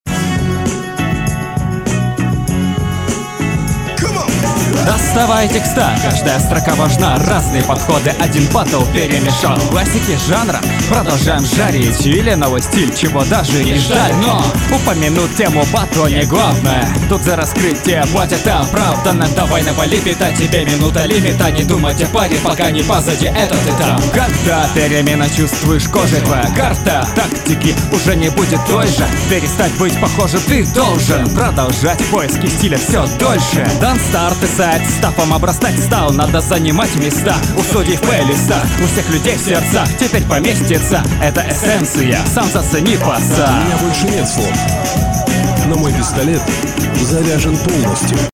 Гармонии с битом совсем нет. :(
Яркий стиль, неплохо